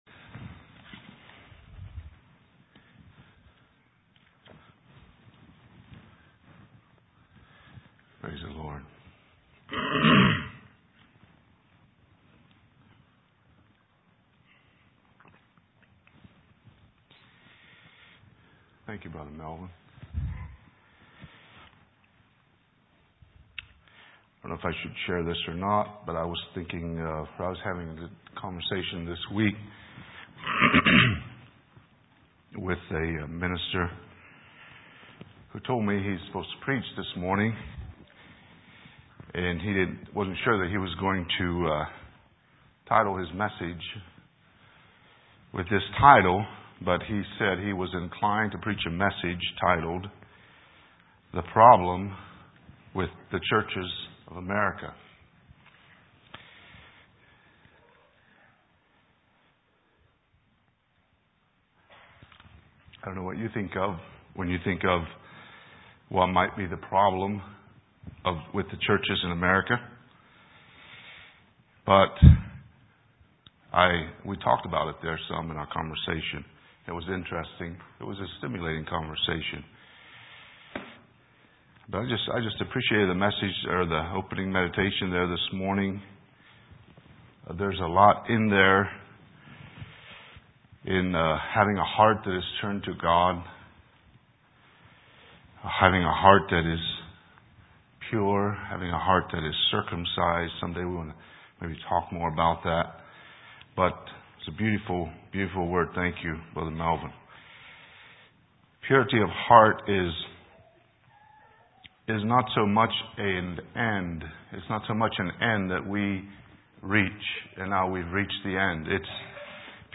2023 Sermons 9/14